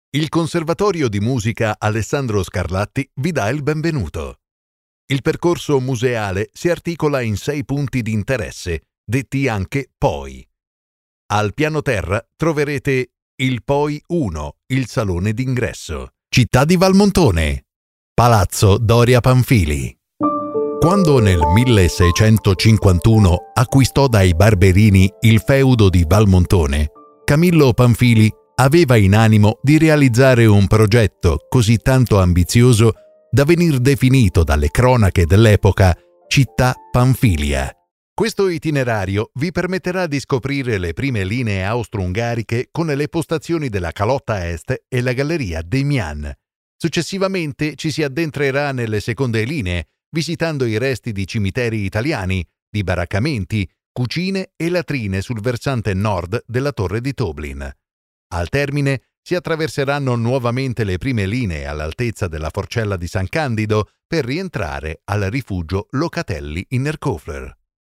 Explainer Videos
My voice is deep, mature, warm and enveloping, but also aggressive, emotional and relaxing.